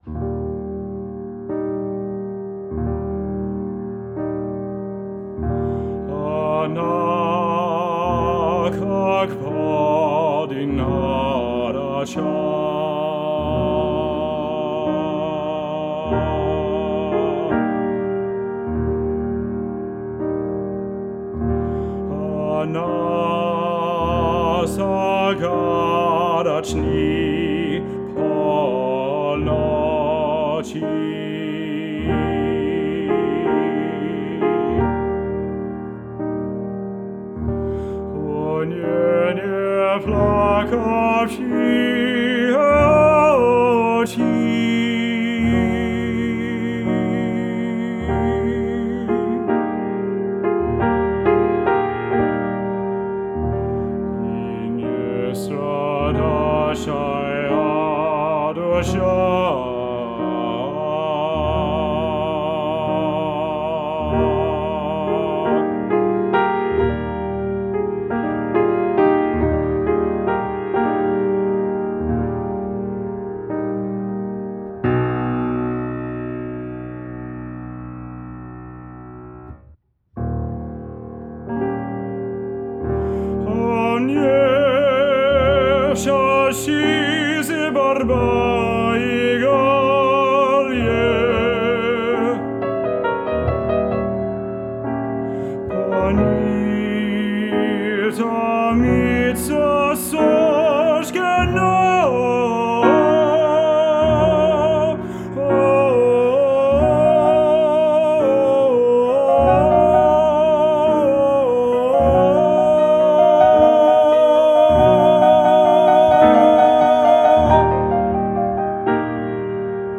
Solo Voice
Classical:
Voice
Piano